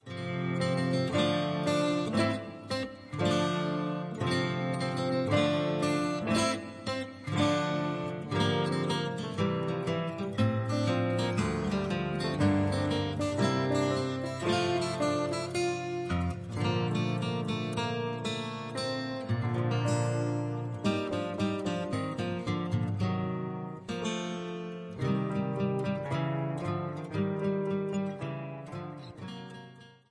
The live piano accompaniment that plays with the album
Instrumental